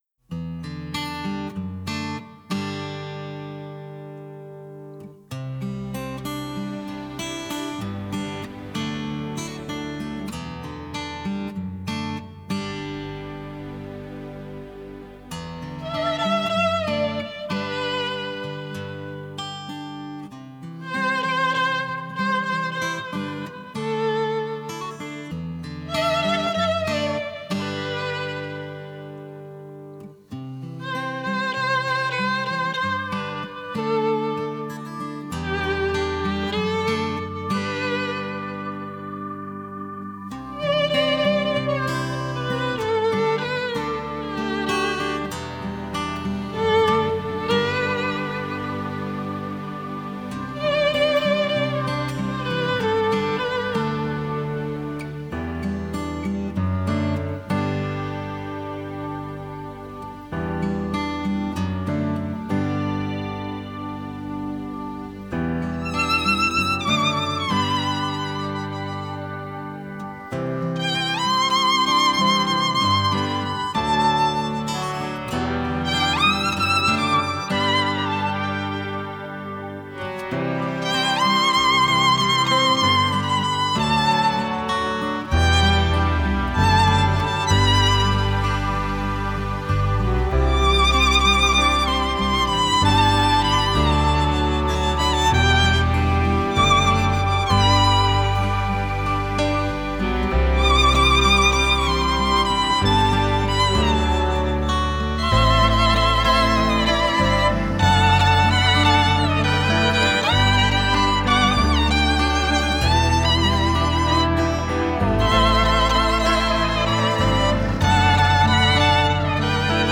Genre: Score